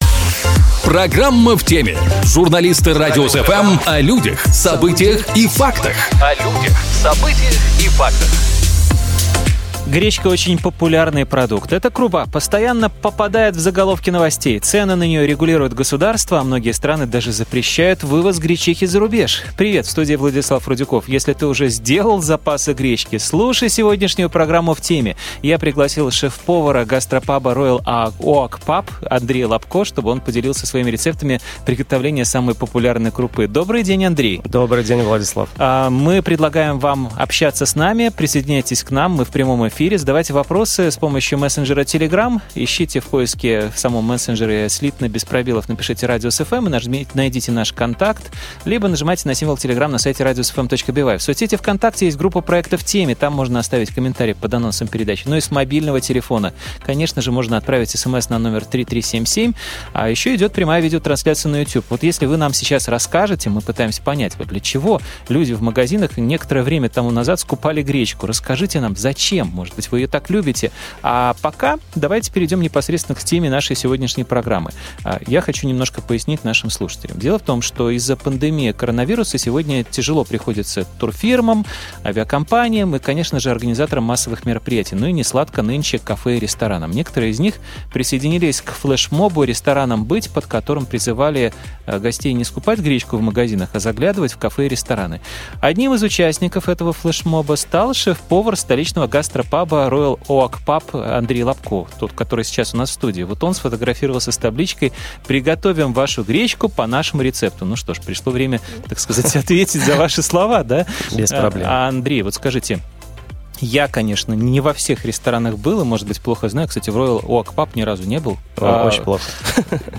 Мы пригласили шеф-повара гастро-паба, чтобы он поделился своими рецептами приготовления самой популярной крупы.